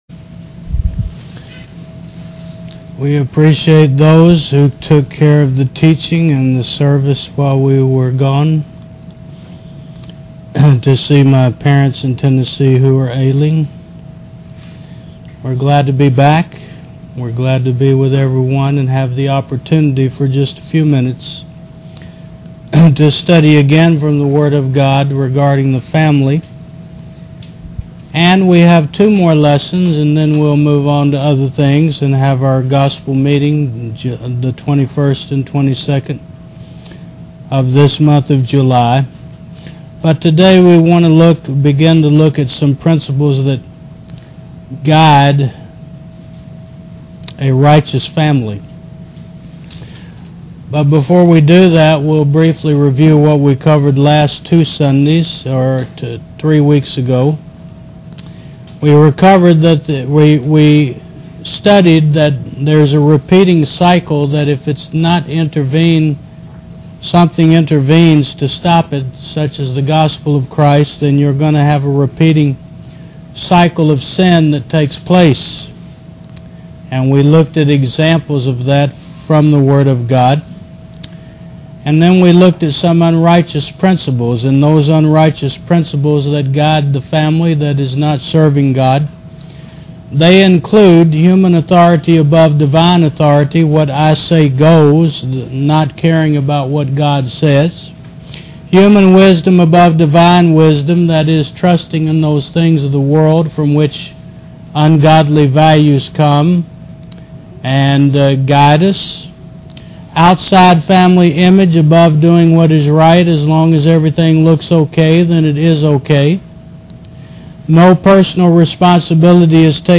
Service Type: Sun. 11 AM